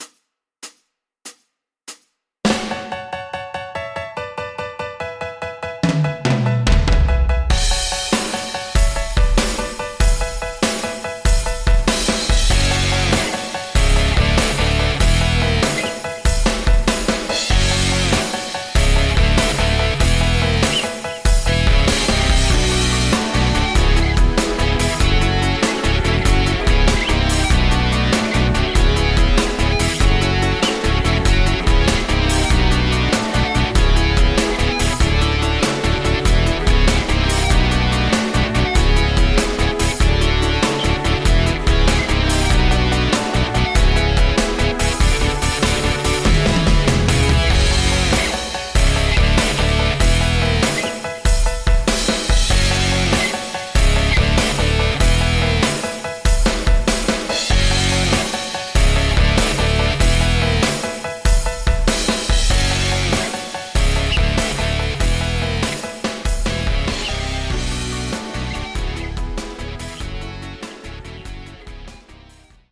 Midi, Karaoke, Instrumental